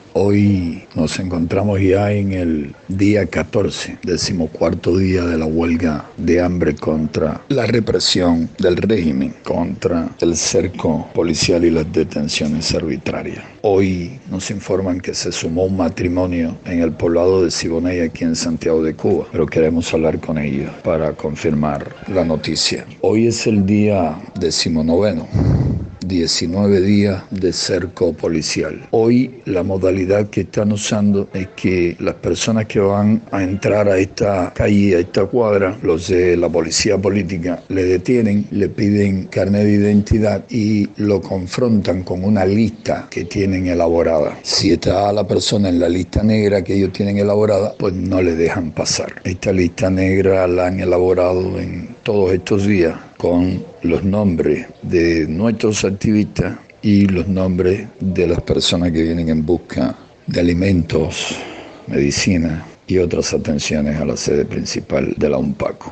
Declaraciones de José Daniel Ferrer a Radio Martí